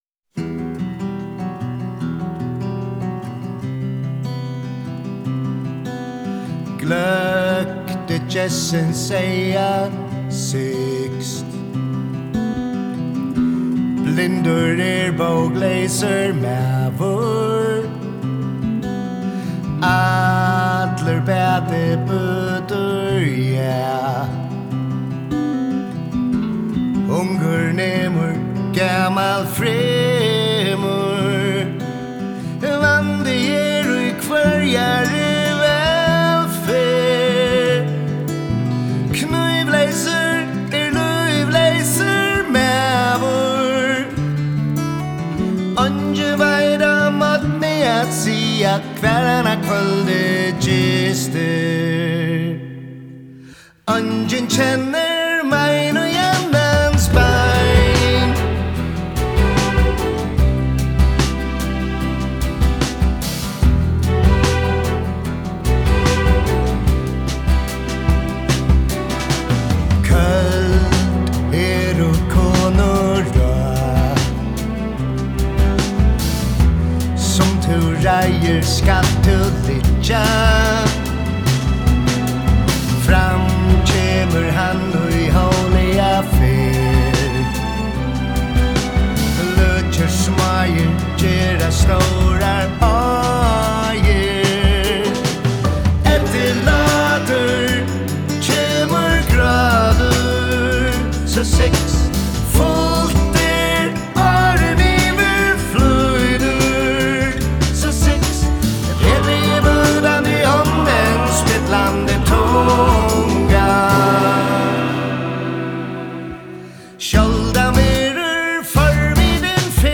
hevur verið í studio og innspælt ein sang.
Trummur
Bass
Kór
Strings